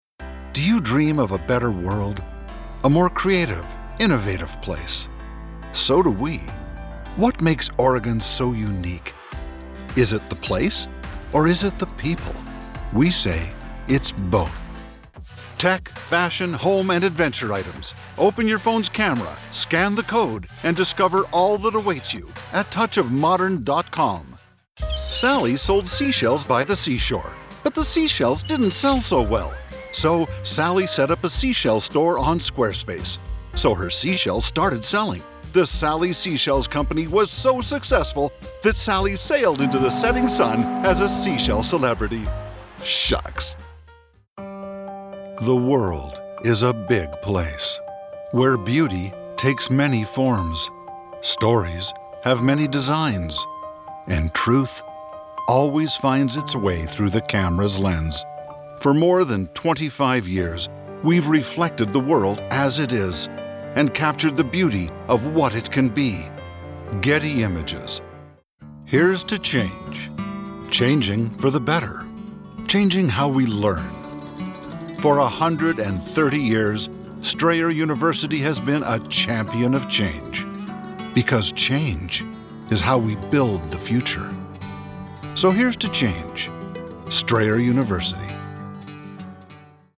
Commercial Demo Reel
Middle Aged